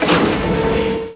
mineralScoop.wav